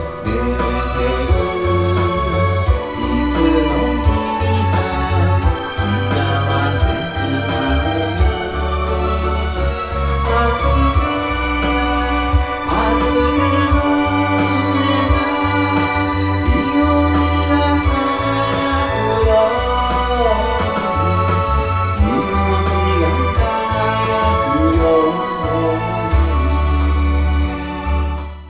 松本零士さん追悼ということで「うたってみた」
スマホの空間録りのため音質は気にしないよう (音が外れているのは単に音痴だから)。